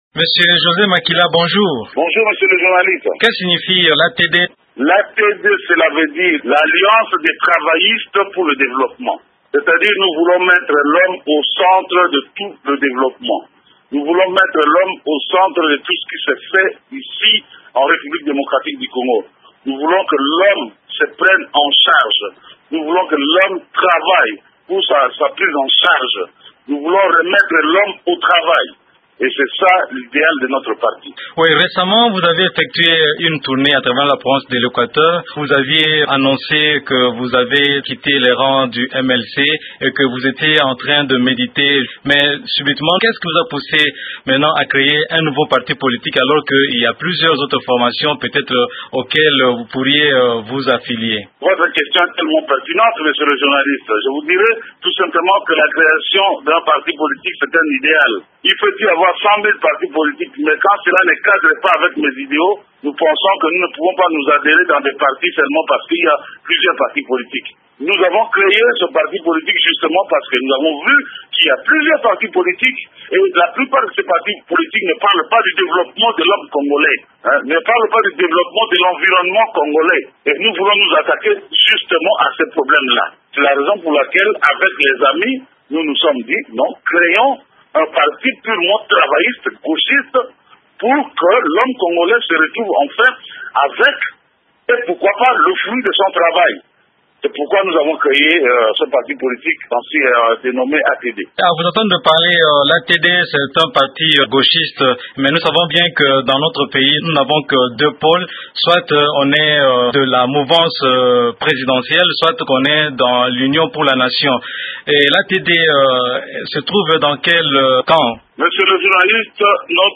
José Makila répond aux questions